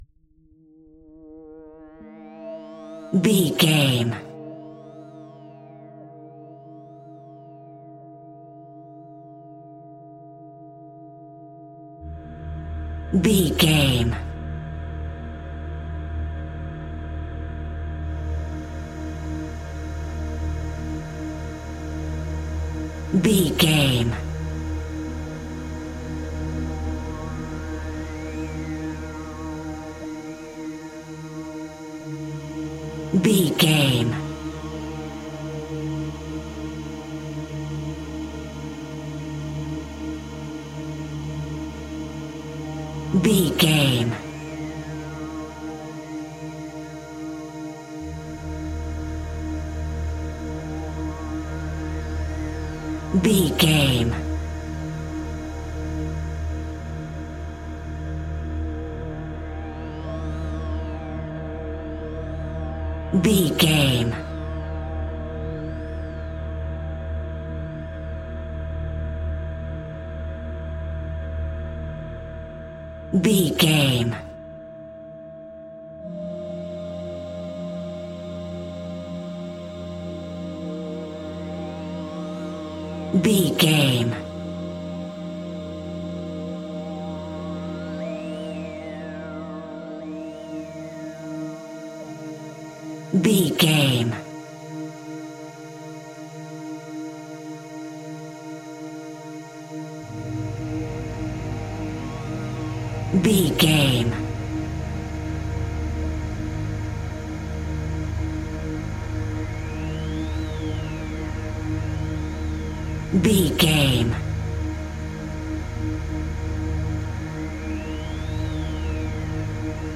Aeolian/Minor
E♭
Slow
ominous
dark
eerie
strings
synthesiser
horror
pads